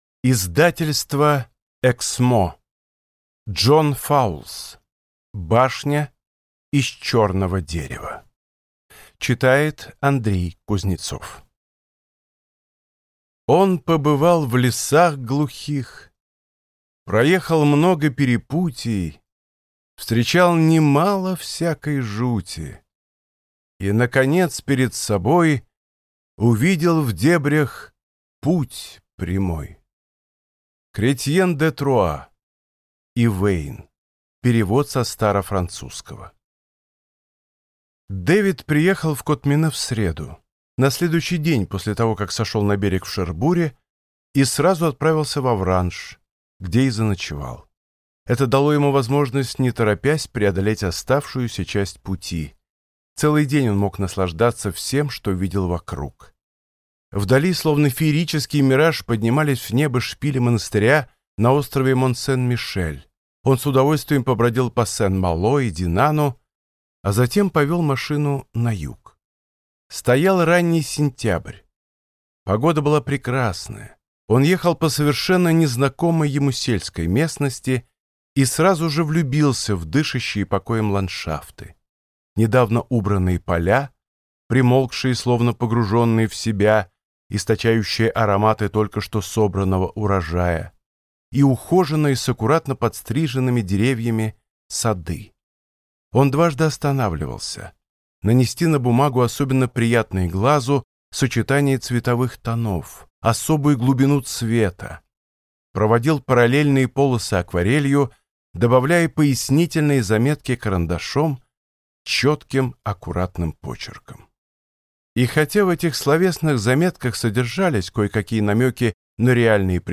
Аудиокнига Башня из черного дерева | Библиотека аудиокниг